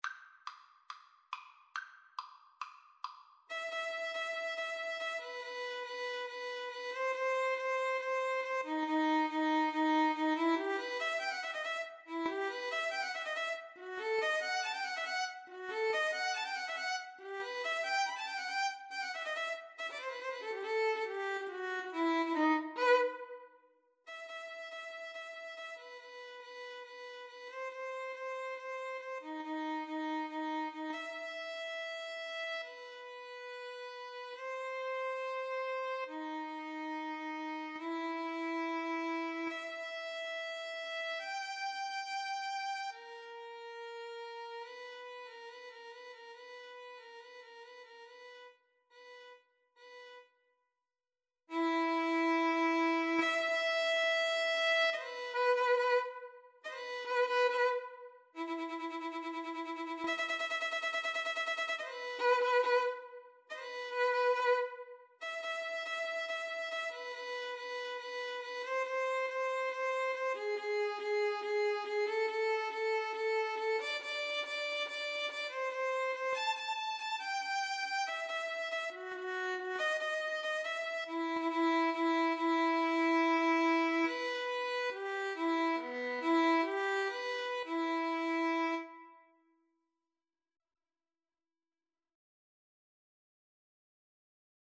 4/4 (View more 4/4 Music)
Allegro con brio = c. 140 (View more music marked Allegro)
Classical (View more Classical Violin Duet Music)